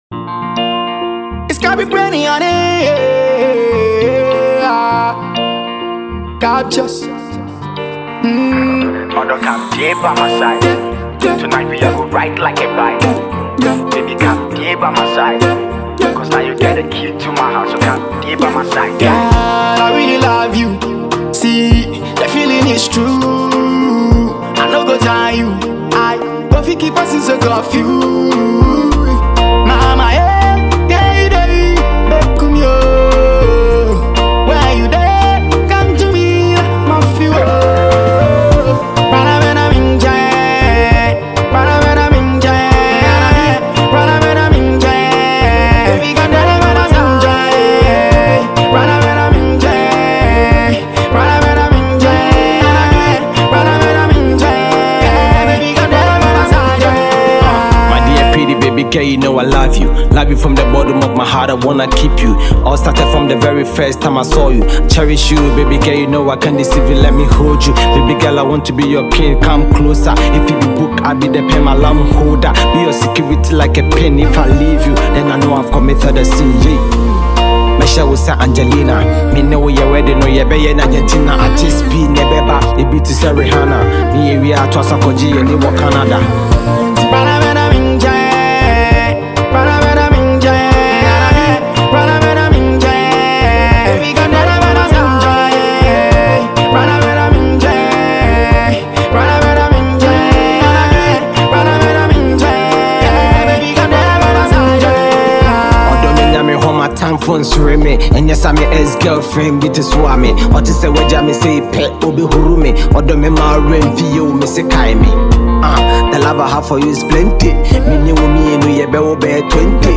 classic love tune
to add his soothing voice to the tune.